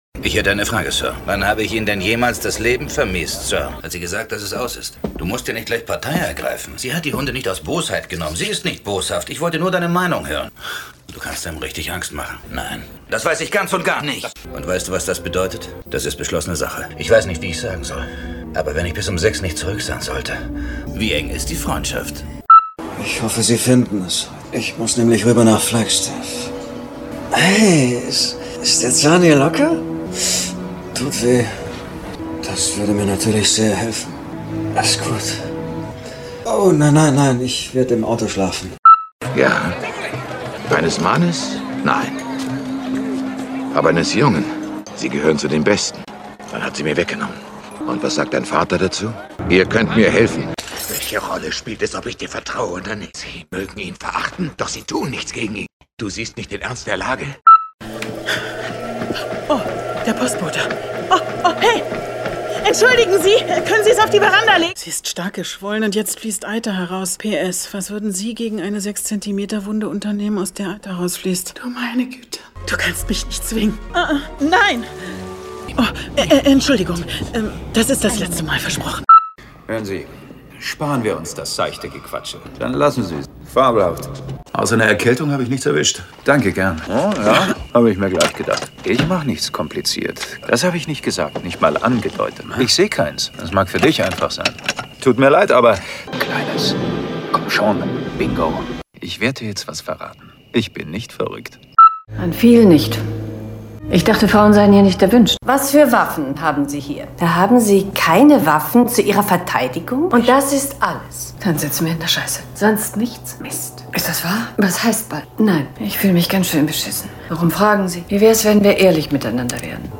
Irgendwann sind mir die verschiedenen Stimmen aufgefallen, die bei einem Darsteller bzw. einer Darstellerin oft die gleichen sind. Diese Stimmen findet man aber nicht nur in den Spielfilmen wieder, sondern sie sind auch in Serien, Hörspielen und Dokumentationen und der Werbung wieder zu erkennen.